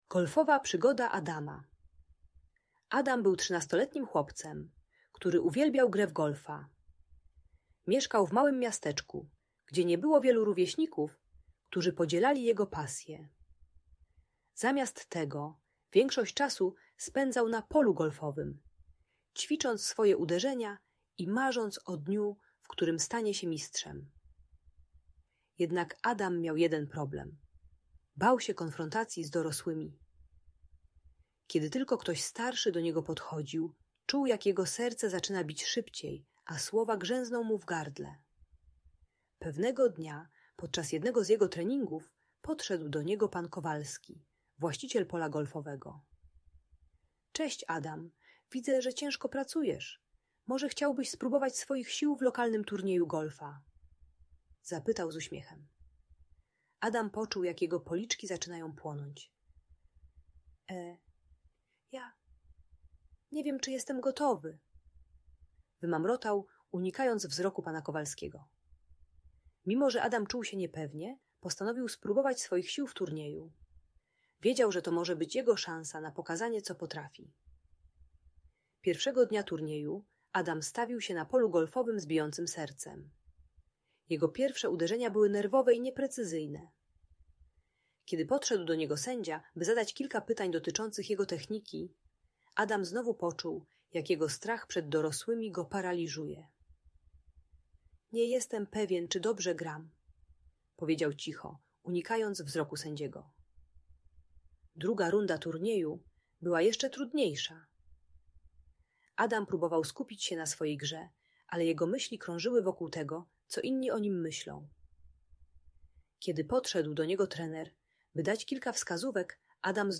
Uczy techniki głębokiego oddechu i kontaktu wzrokowego, by pokonać lęk przed autorytetami. Audiobajka o przezwyciężaniu nieśmiałości wobec dorosłych.